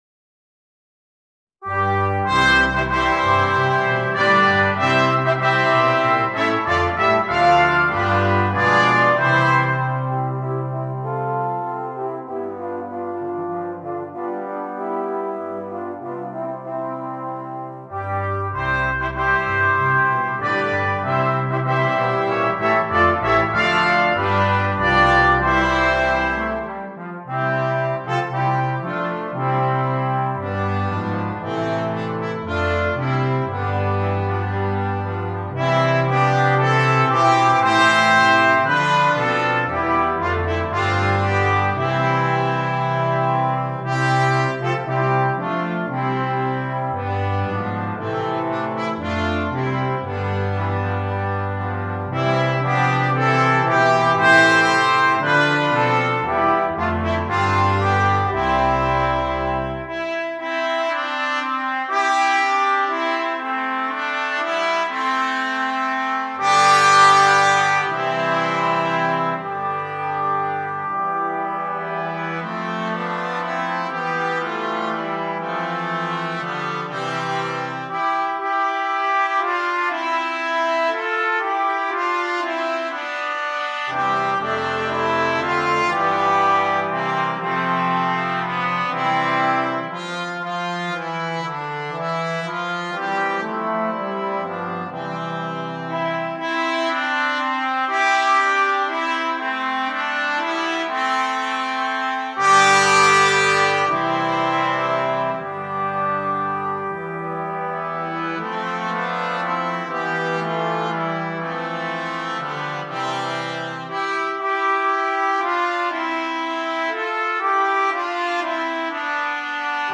Voicing: Brass Ensemble